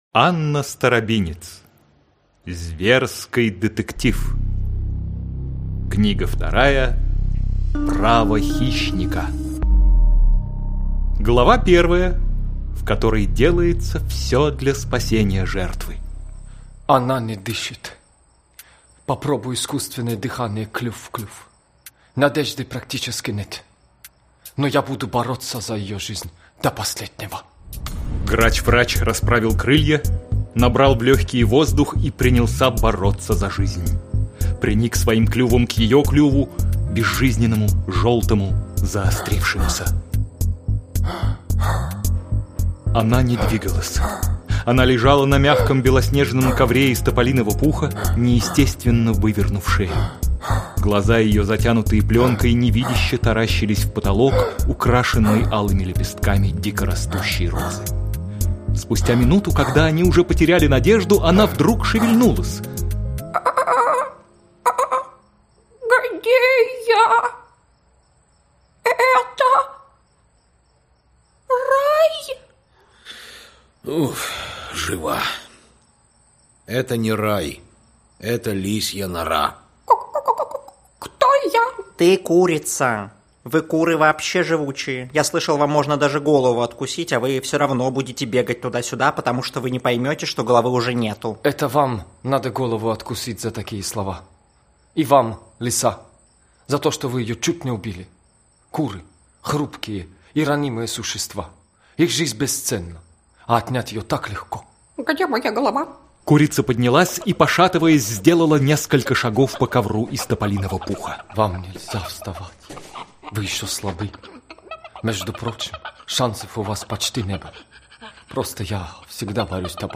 Аудиокнига Зверский детектив. Право хищника (аудиоспектакль) | Библиотека аудиокниг